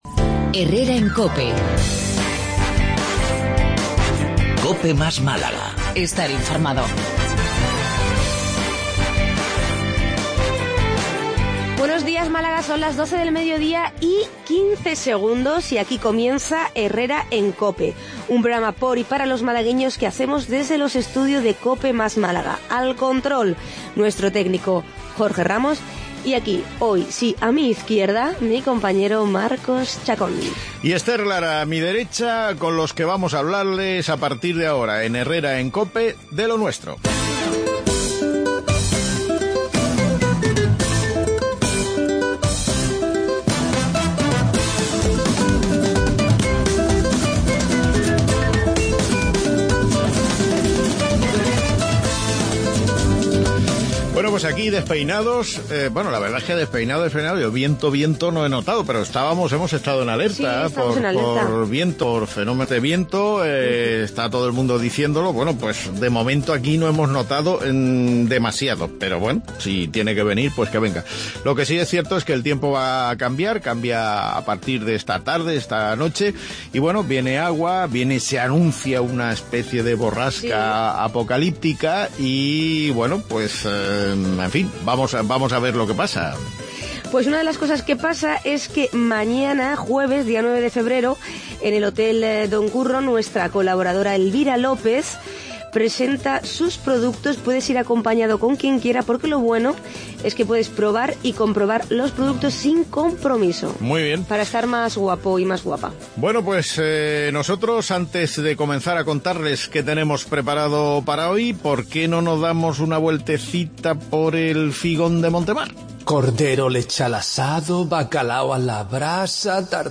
Magazine diario